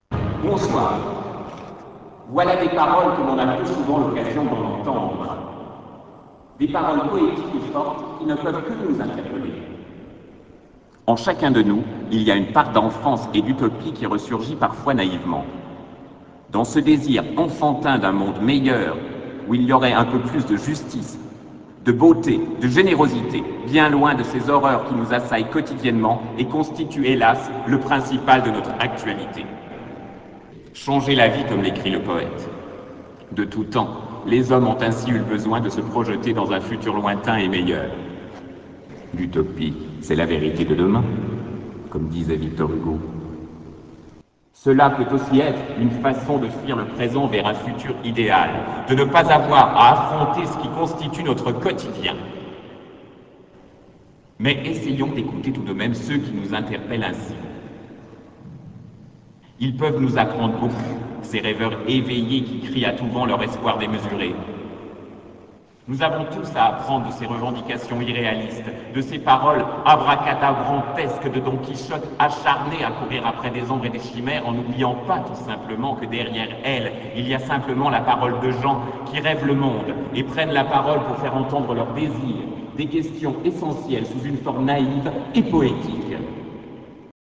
Présentation publique devant plus de 500 spectateurs.